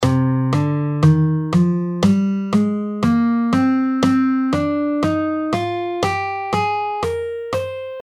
C Aeolian (C Minor)
C Aeolian (C Minor): C – D – E♭ – F – G – A♭ – B♭ – C. The natural minor scale, evoking a sense of melancholy and introspection.
C-Aeolian-6th-Mode-Of-C-Major.mp3